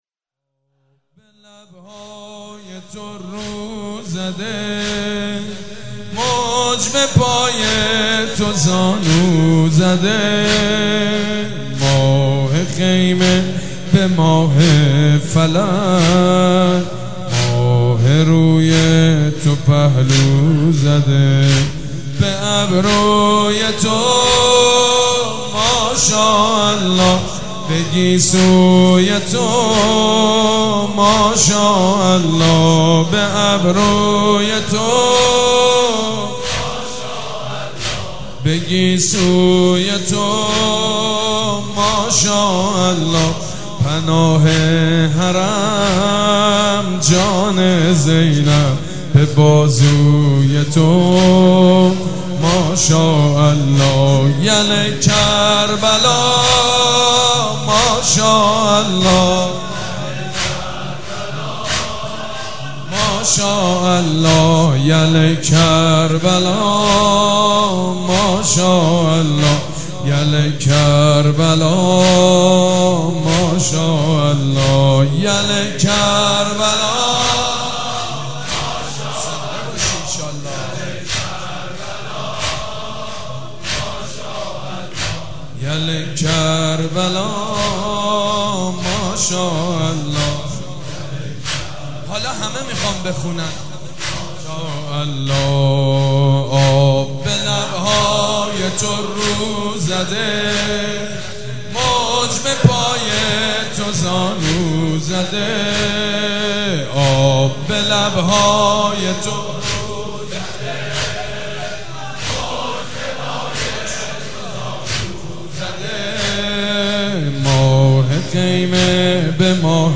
مراسم شهادت حضرت ام البنین سلام الله علیها
مداح
حاج سید مجید بنی فاطمه